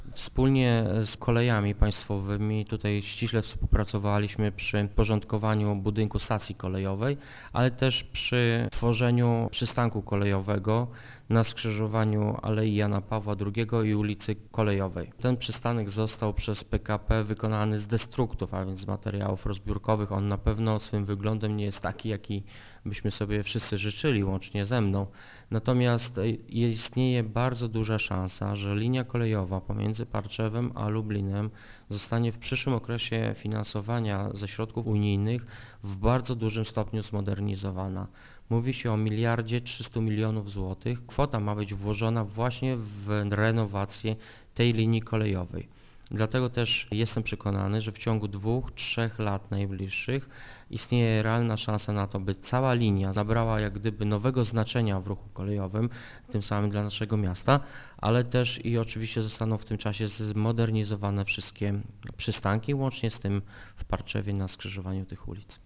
Wszystko co jest niezbędne do wznowienia połączeń zostało już przygotowane - mówi burmistrz Parczewa Paweł Kędracki: